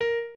piano8_36.ogg